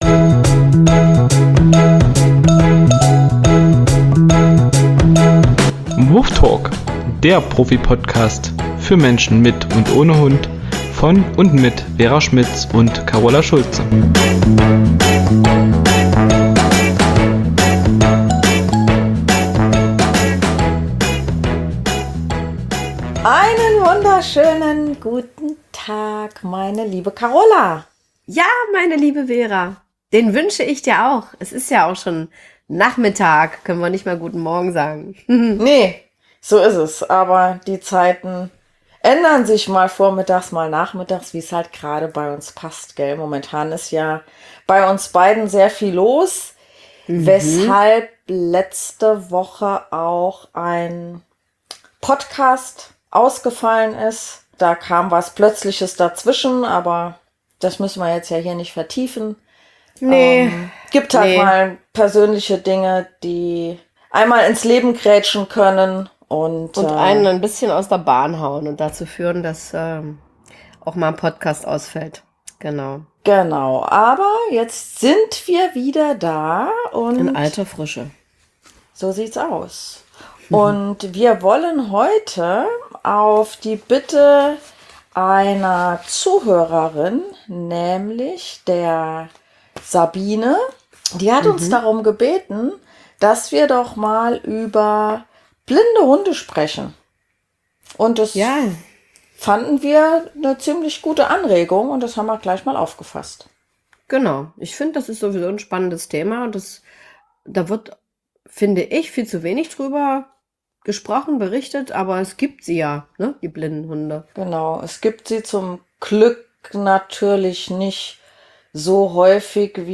Überwiegend geht es natürlich um Be- und Erziehung. Die beiden räumen mit Mythen auf, geben jede Menge Tipps, plaudern hier und da aus dem Nähkästchen und lassen dich an skurrilen und sensationellen Erlebnissen teilhaben, die sie bei ihrer Arbeit mit Menschen und Hund manchmal machen.